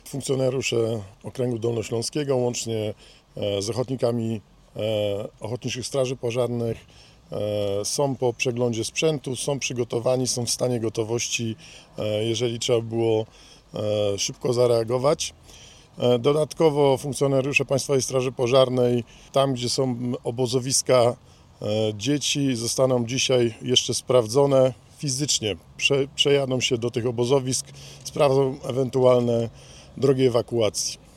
Komendant wojewódzki Państwowej Straży Pożarnej – nadbryg. Marek Hajduk zapewnił o gotowości strażaków na sytuację kryzysową.
na-strone_5_strazak-o-gotowosci-i-zabezpieczeniu-obozow.mp3